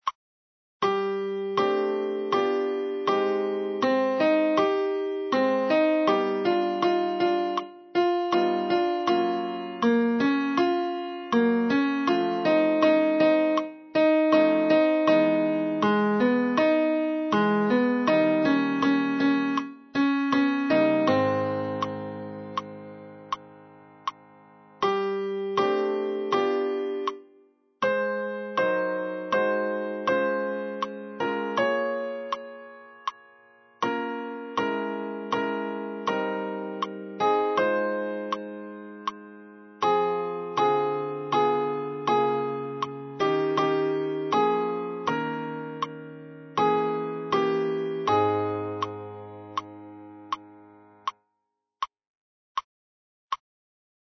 Bonjour – Sopran